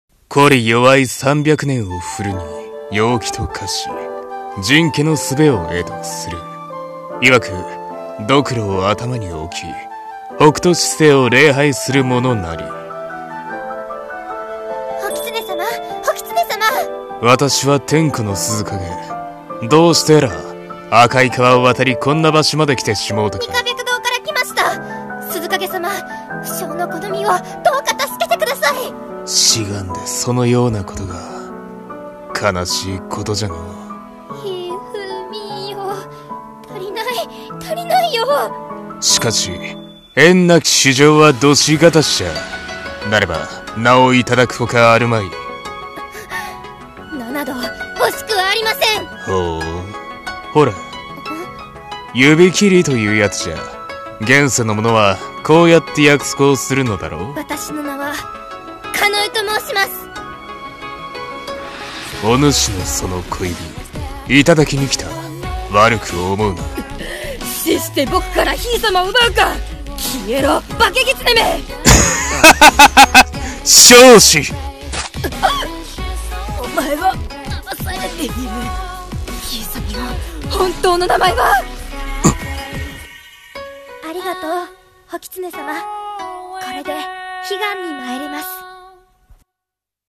CM風声劇「狐の余命入り」